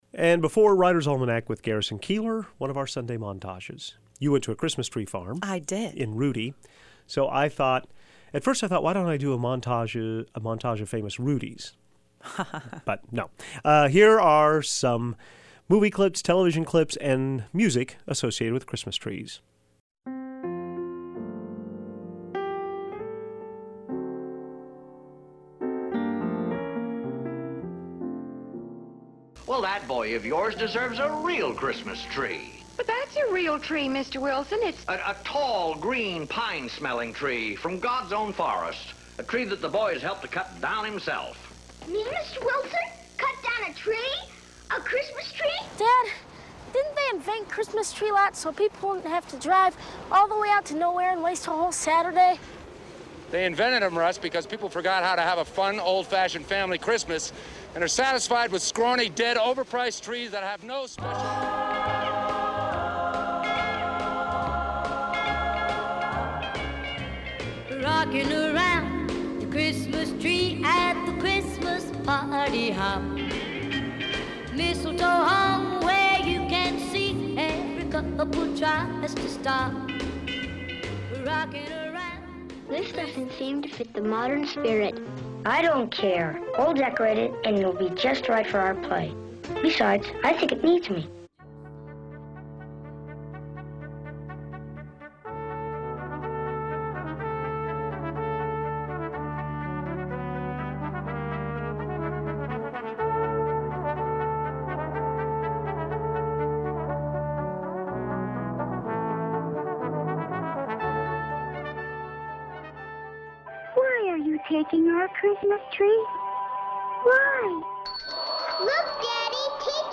Here are the eleven elements of today's Christmas tree montage:
1. Miles Davis' version of "O Christmas Tree"
11. Wynton Marsalis gives "O Christmas Tree" a bit of New Orleans bounce